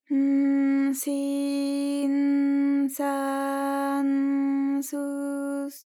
ALYS-DB-001-JPN - First Japanese UTAU vocal library of ALYS.
s_n_si_n_sa_n_su_s.wav